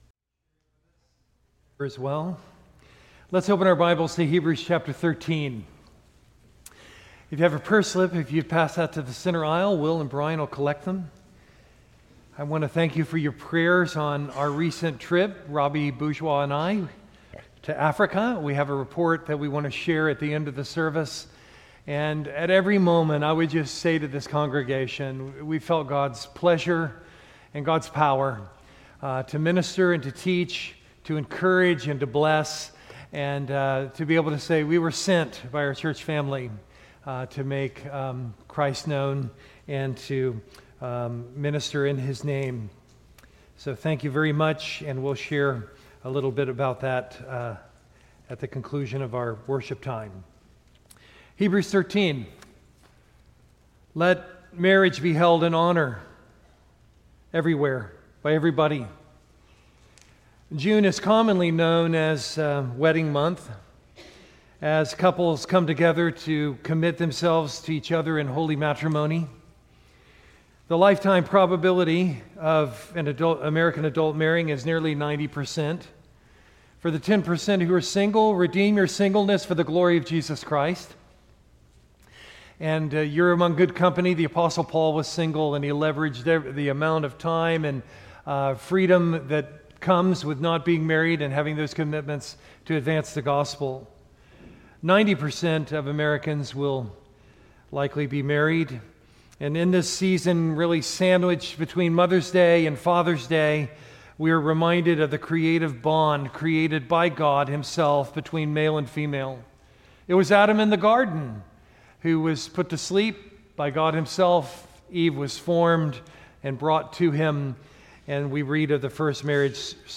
Sermon content from First Baptist Church Gonzales, LA